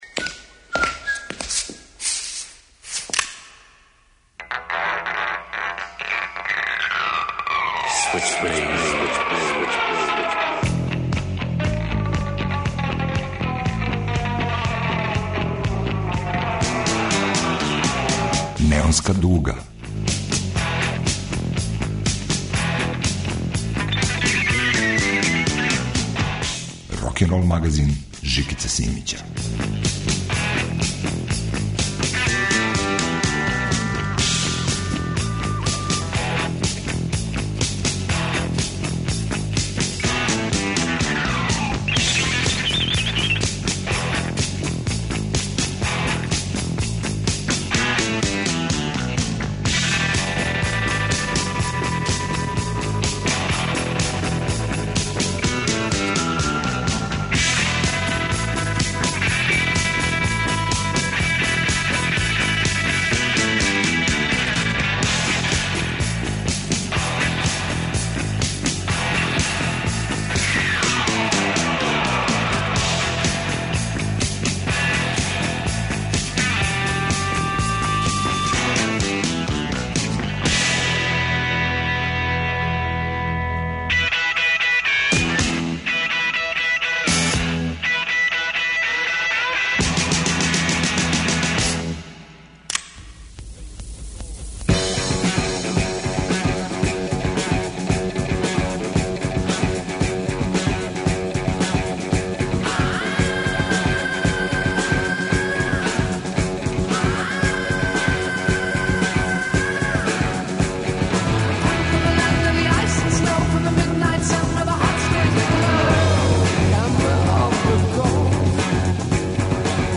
Опојни музички коктел од хеви рока и пасторалних балада.